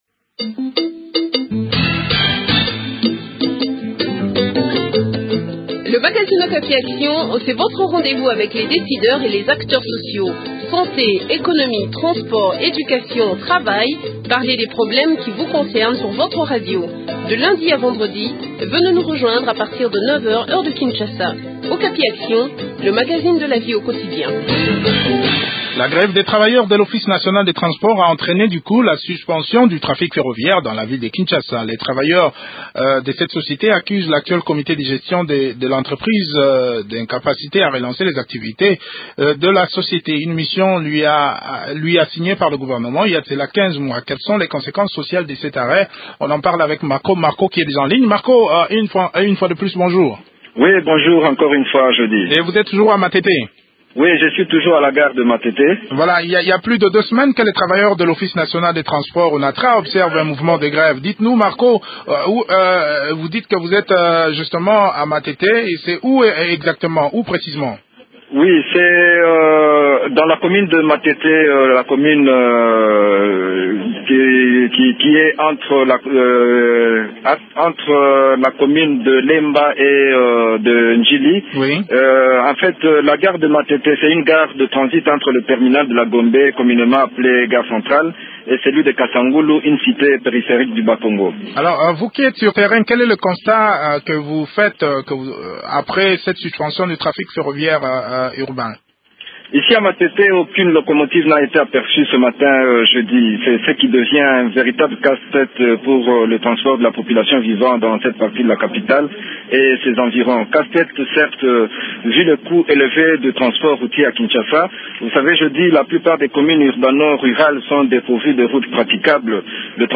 Rody Mpika, ministre provincial du transport